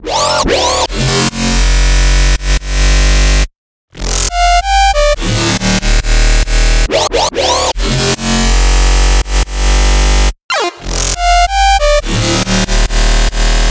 标签： 140 bpm Dubstep Loops Bass Wobble Loops 2.31 MB wav Key : F
声道立体声